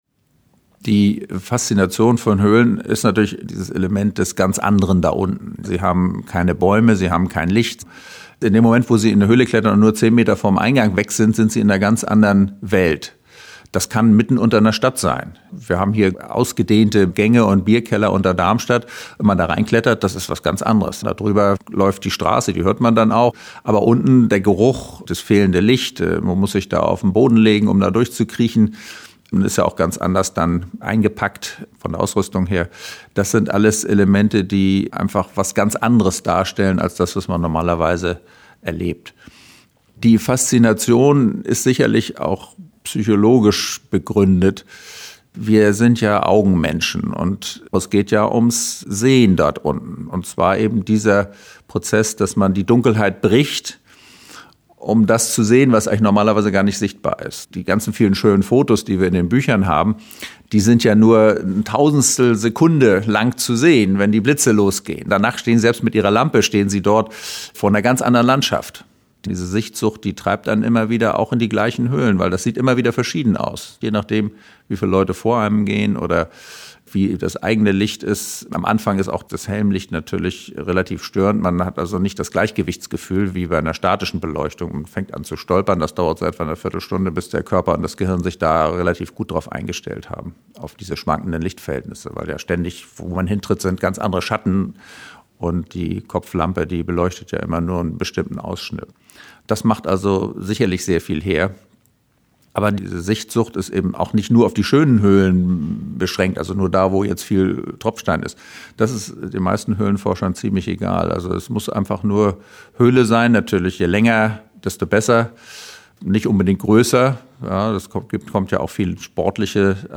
Höhlenforscher erzählen